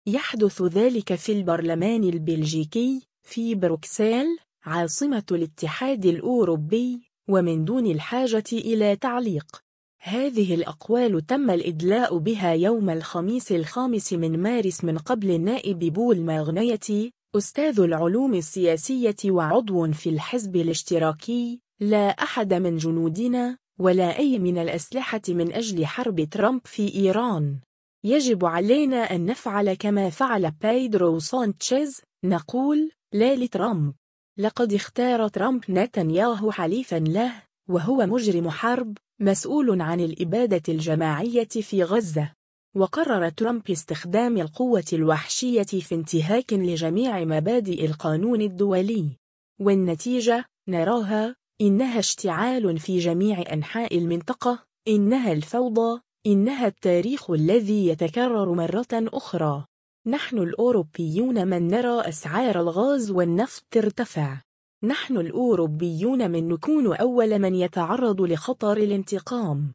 يحدث ذلك في البرلمان البلجيكي، في بروكسل، عاصمة الاتحاد الأوروبي، ومن دون الحاجة إلى تعليق. هذه الأقوال تم الإدلاء بها يوم الخميس 5 مارس من قبل النائب بول ماغنيتي، أستاذ العلوم السياسية وعضو في الحزب الاشتراكي:
في نفس اليوم، صرح راوول هيدبو، رئيس حزب العمل البلجيكي، بما يلي حول طلب بشأن السفير الإيراني في بروكسل: